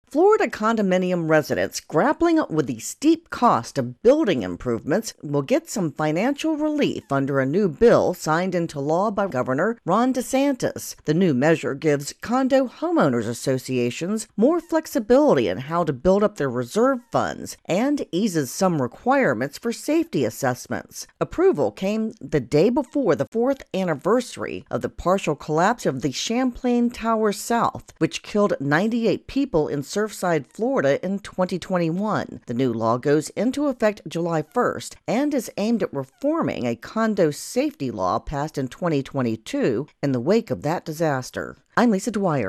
reports on a new condo bill in Florida.